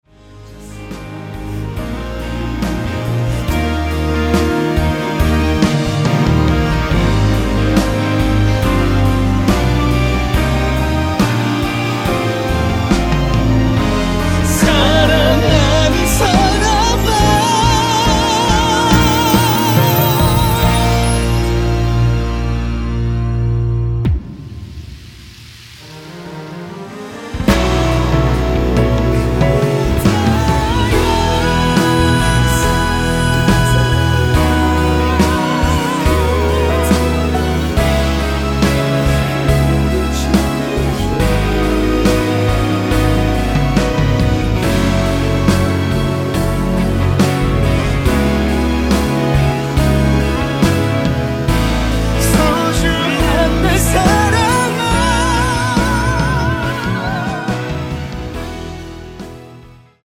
원키 코러스 포함된 MR입니다.
Eb
앞부분30초, 뒷부분30초씩 편집해서 올려 드리고 있습니다.
중간에 음이 끈어지고 다시 나오는 이유는